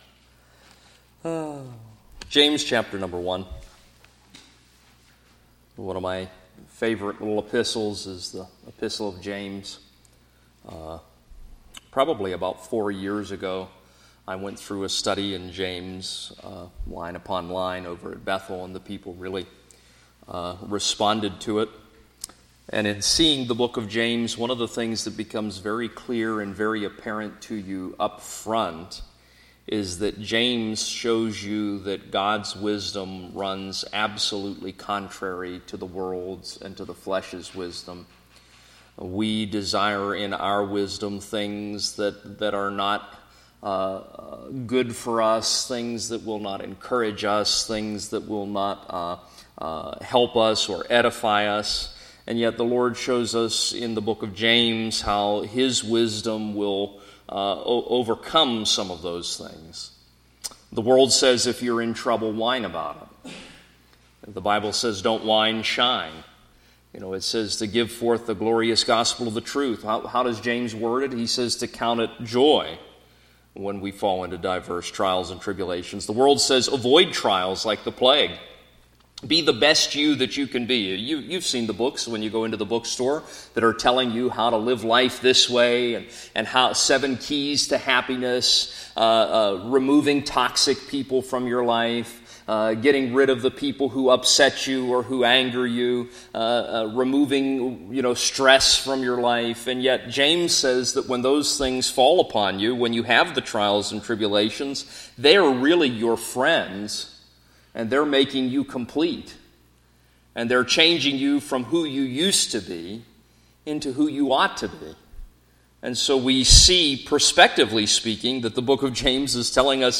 Session: Morning Devotion